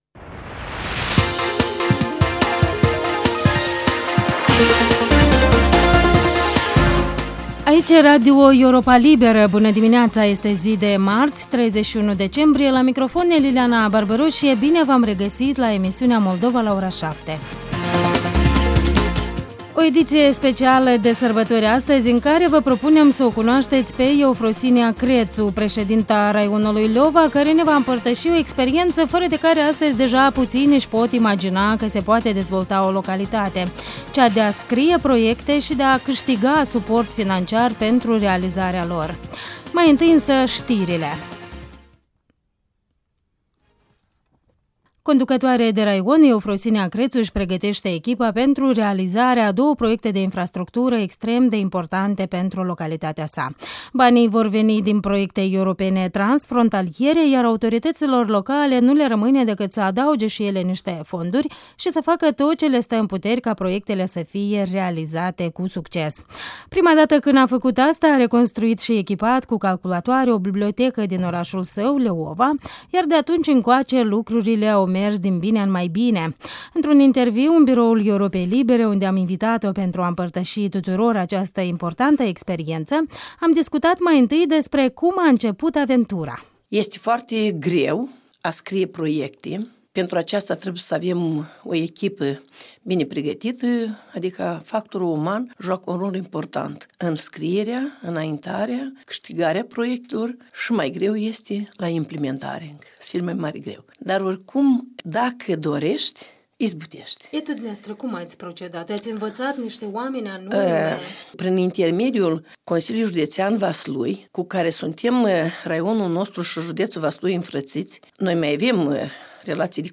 Bilanțuri 2013: interviul dimineții cu Eufrosinia Crețu, președinta raionului Leova